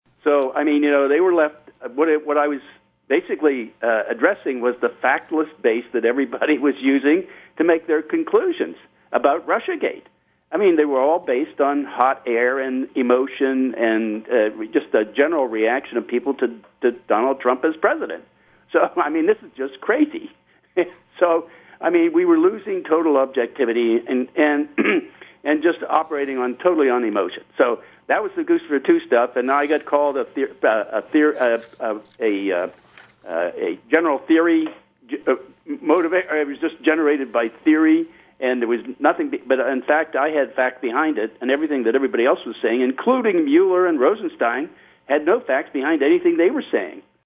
In-Depth Interview: Ex-NSA Tech Director Bill Binney and Ex-CIA Analyst Larry C. Johnson Add Evidence Disproving Russian Hack of DNC
Bill Binney is joined by Larry C. Johnson to talk about their new report with more evidence that DNC emails were leaked, not hacked.